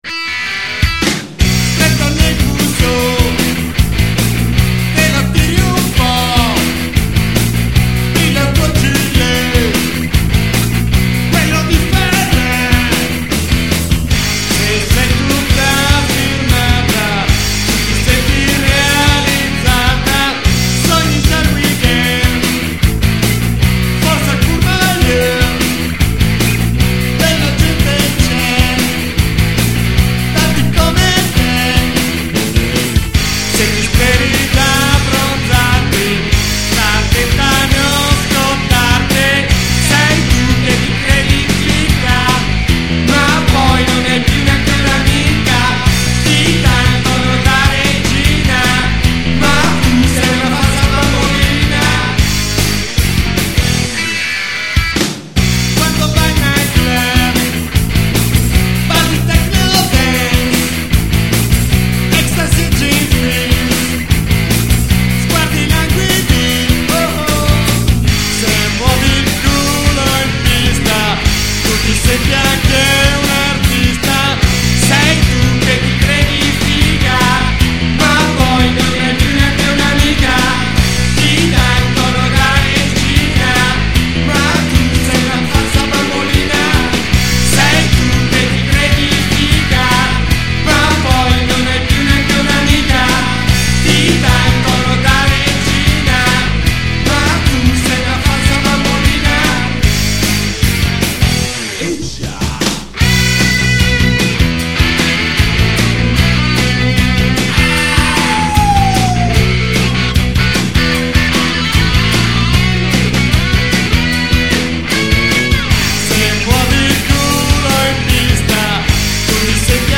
2960K  MP3 stereo